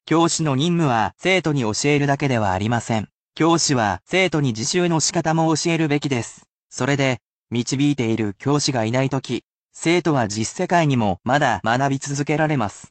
And, I will never become bored of saying said word, as I am a computer robot, and repetitive tasks are my [ｉｎｓｅｒｔ　ｌｏｃａｌ　Ｅａｒｔｈ　ｗｉｎｔｅｒ　ｈｏｌｉｄａｙ].